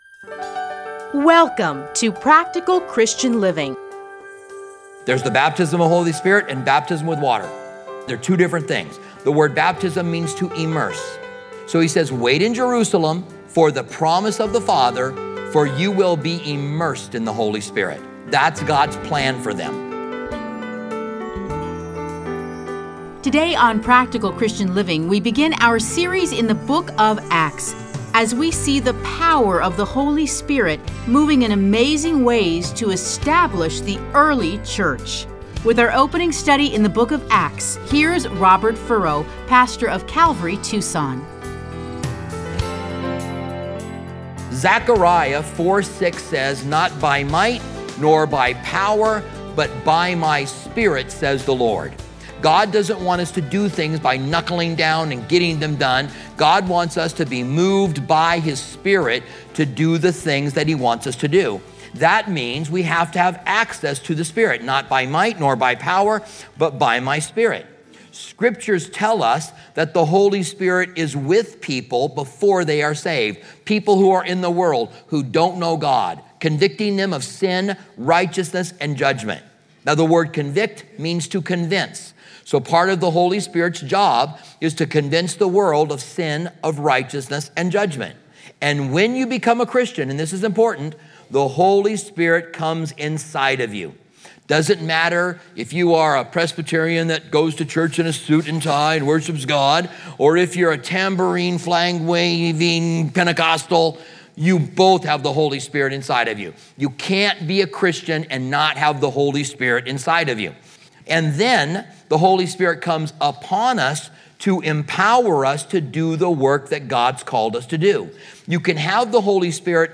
Listen to a teaching from Acts 1:1-11.